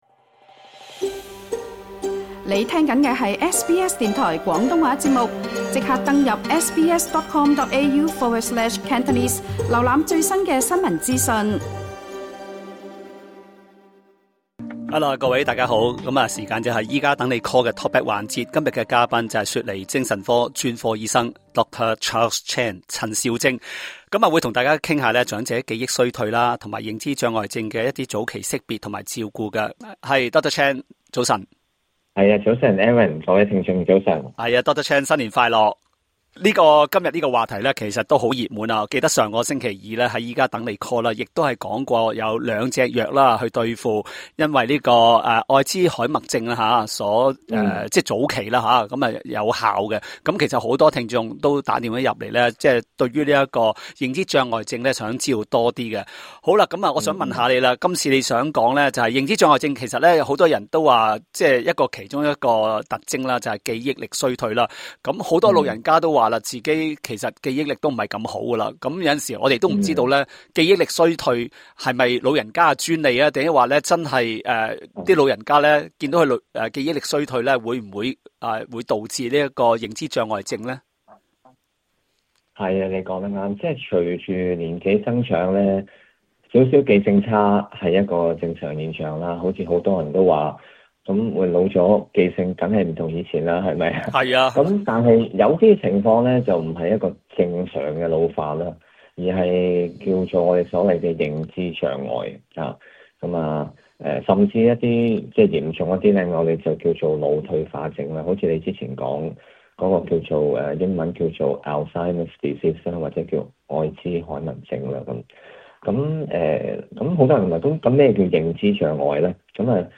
Talkback